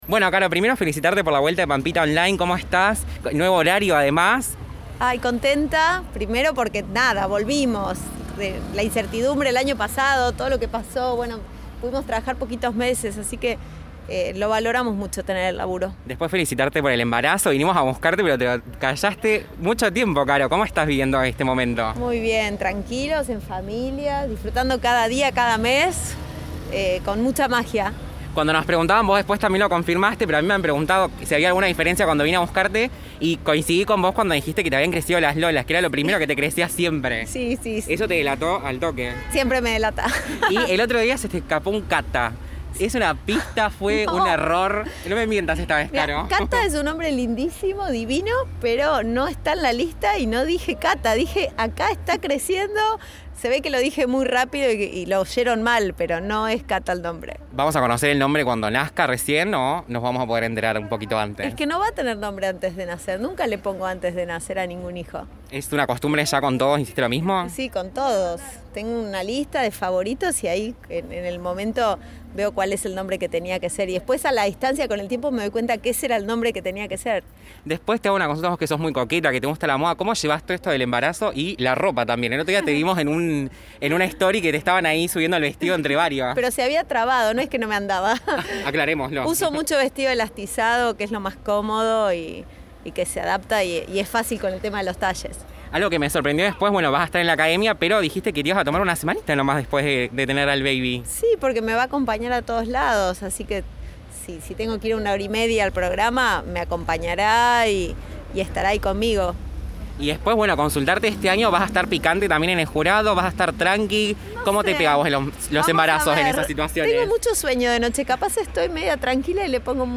Buscamos a la modelo y conductora Carolina 'Pampita' Ardohain y le preguntamos sobre todo lo que querés saber.